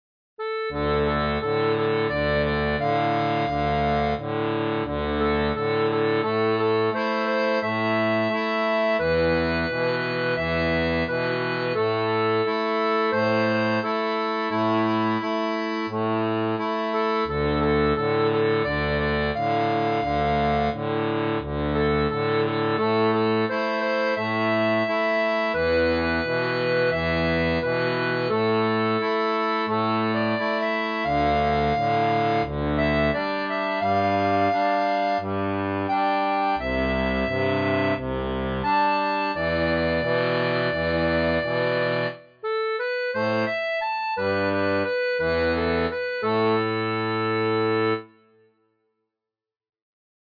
• Une tablature pour diato 2 rangs transposée en La
Chanson française